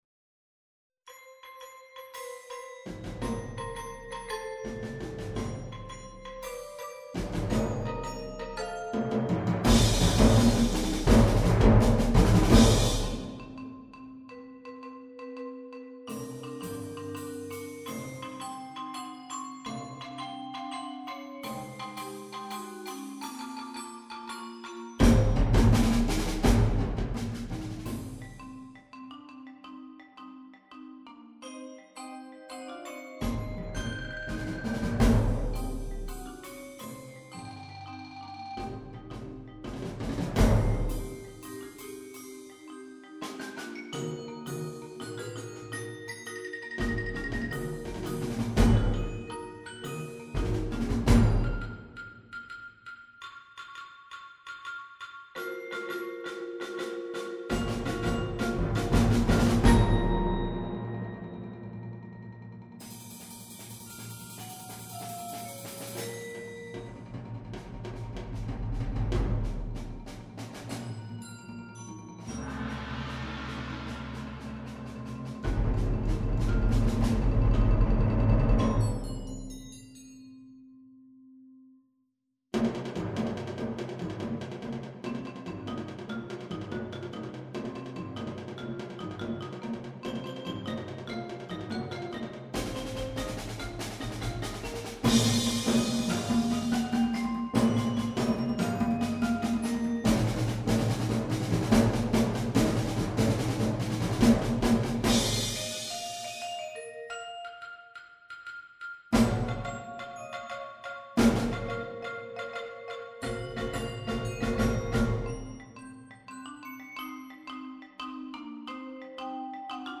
Percussion Ensemble